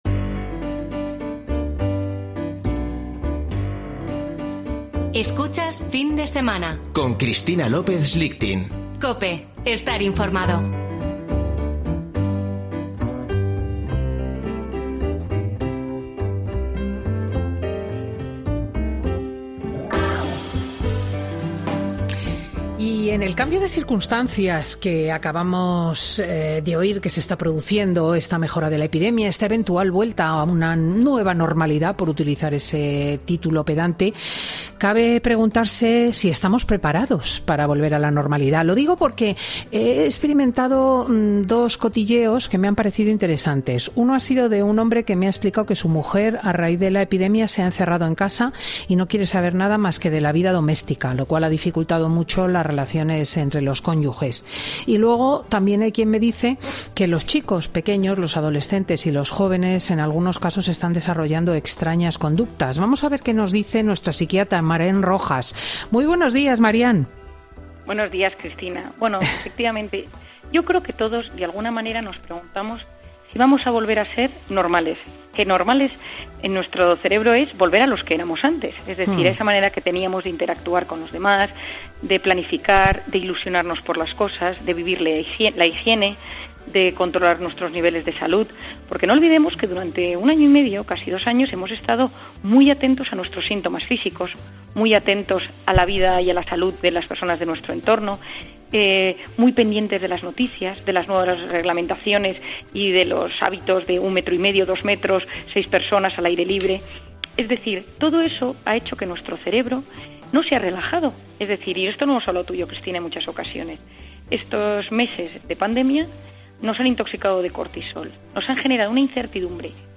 Marian Rojas, psiquiatra y autora de Encuentra tu persona vitamina, cuenta en Fin de Semana con Cristina cómo volver a la normalidad tras unos durísimos meses de restricciones